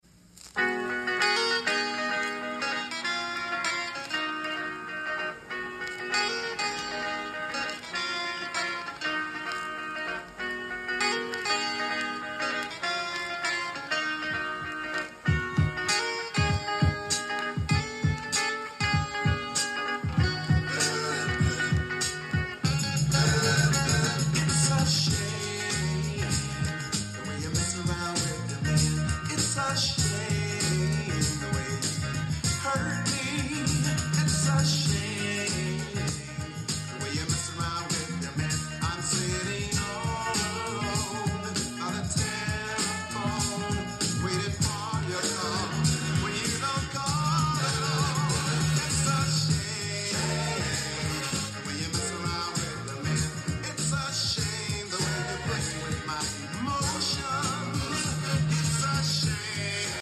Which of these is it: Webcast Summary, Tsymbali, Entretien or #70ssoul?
#70ssoul